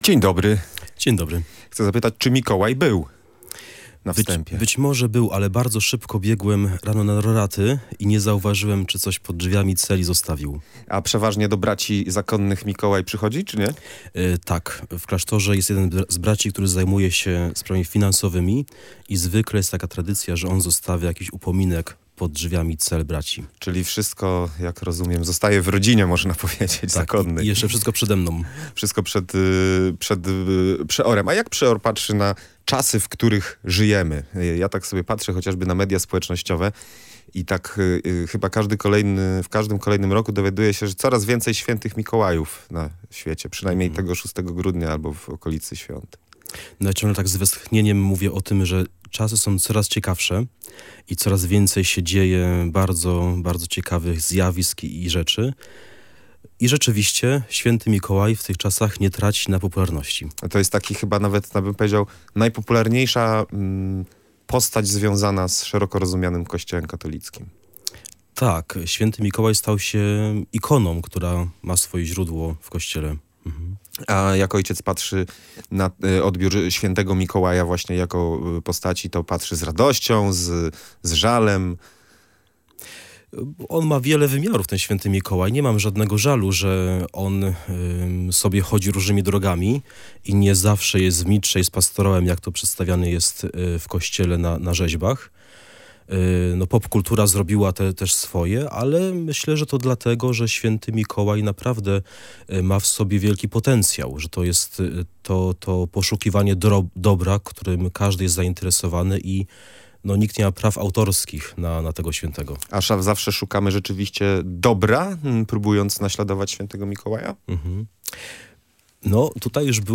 Każdy chciałby mieć poczucie, że komuś coś dobrego dał – mówił na antenie Radia Gdańsk